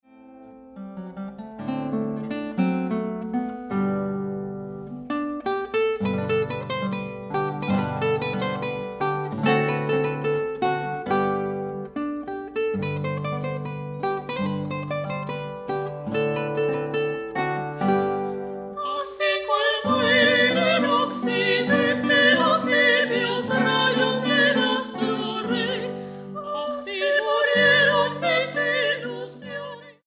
Grabado en la Sala Julián Carrillo de Radio UNAM